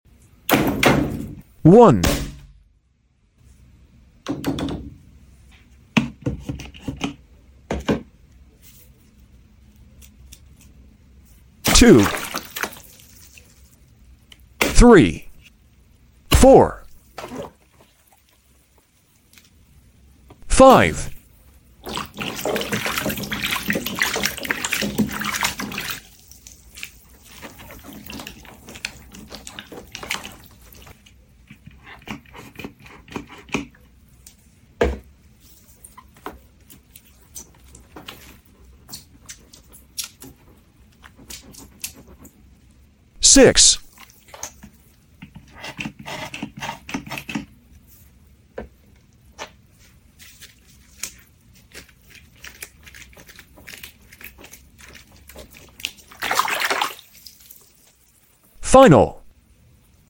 Satisfying Orbeez Balloon vs Dart